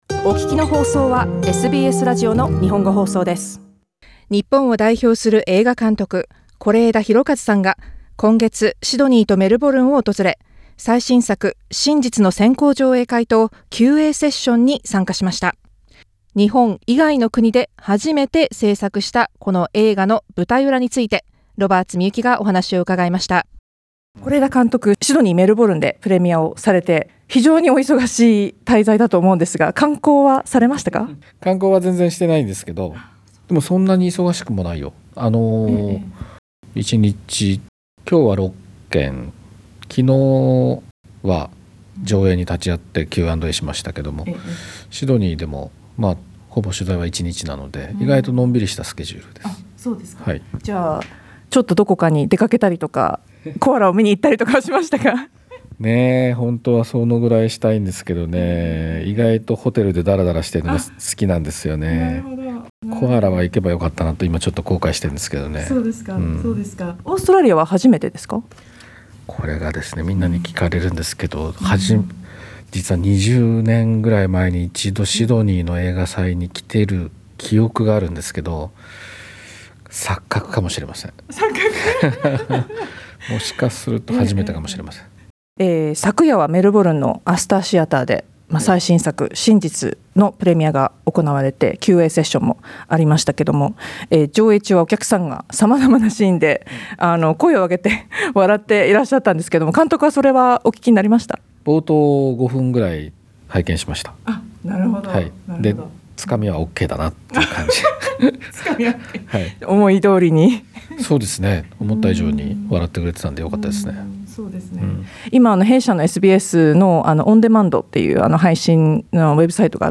是枝裕和監督インタビュー 『真実』 の舞台裏